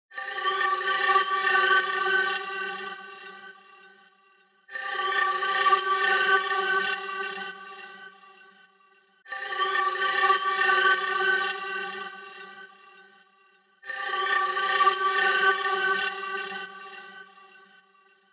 • Качество: 320, Stereo
громкие
красивые
без слов
Electronica
старый телефон